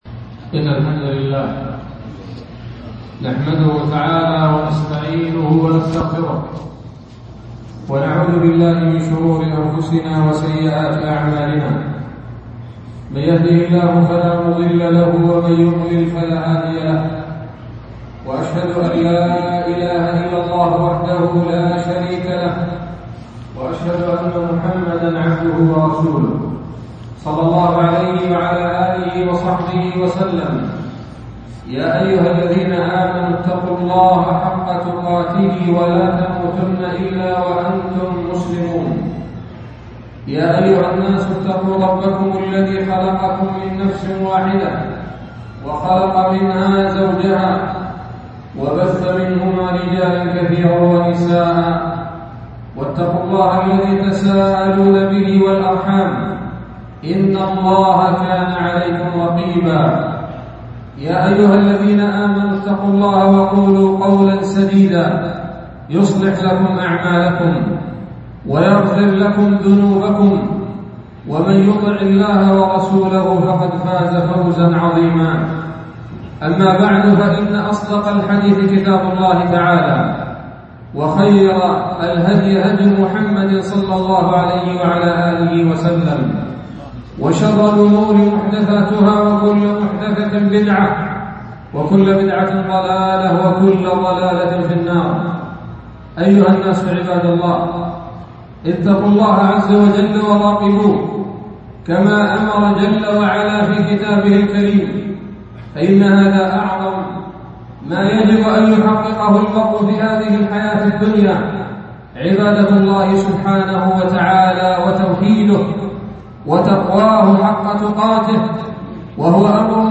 خطبة جمعة بعنوان: (( حقارة الدنيا والإقبال على الله )) 2 من شهر ربيع الآخر 1441 هـ، مسجد السريع - سوق الصواريخ - مدينة جدة